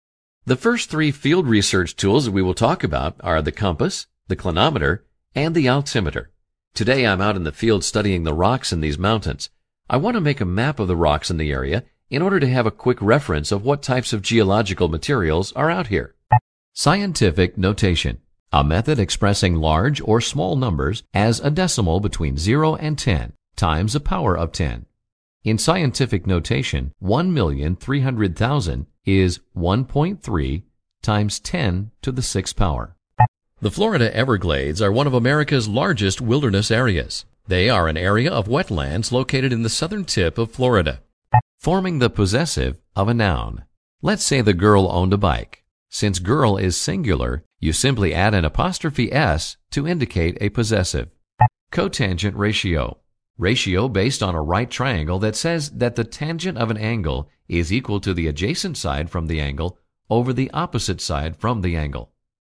I can offer a warm, deep, personable voice over for narrations, training videos and presentations.
Sprechprobe: eLearning (Muttersprache):